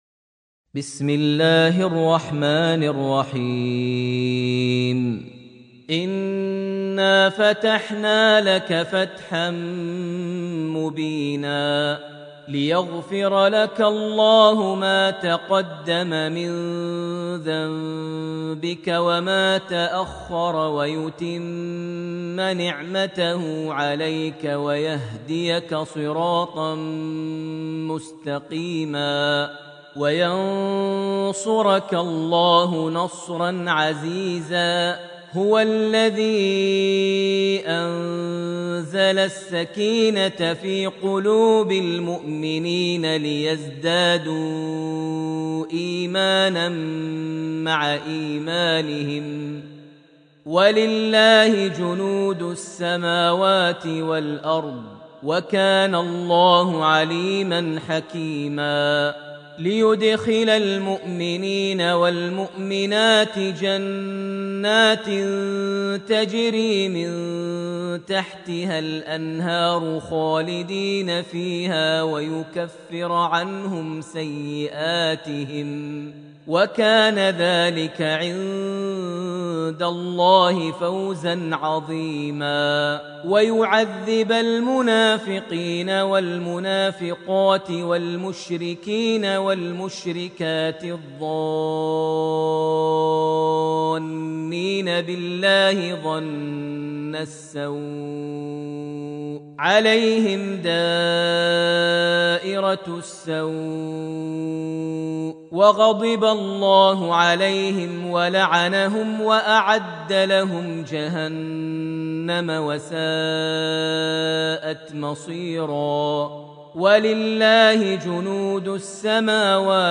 surat Al-Fatih > Almushaf > Mushaf - Maher Almuaiqly Recitations